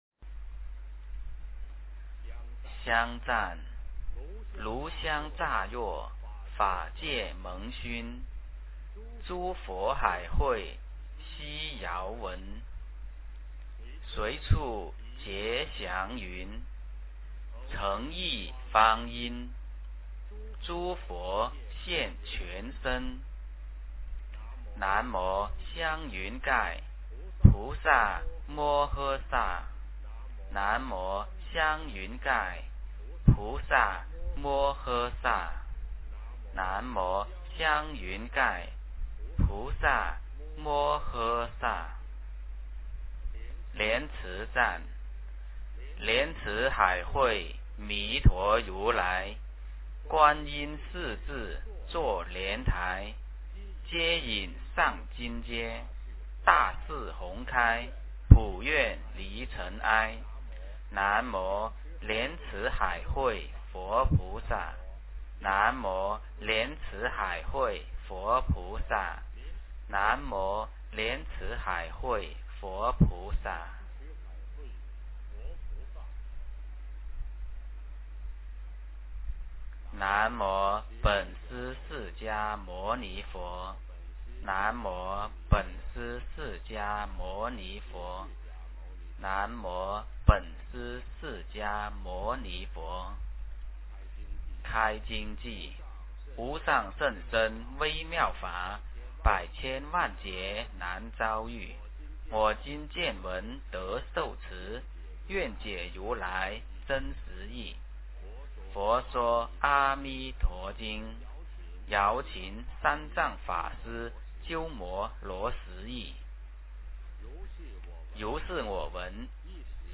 佛说阿弥陀经 - 诵经 - 云佛论坛